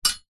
Звук: Металлические медицинские ножницы лежат на металлическом столе